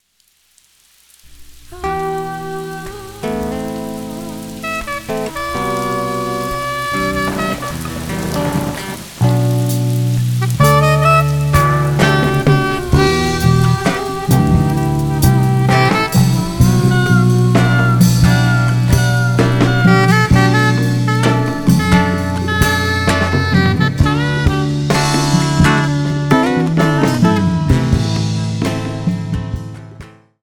Violão/Voz
Backing vocal
Saxofone
Baixo / Percussão / Bateria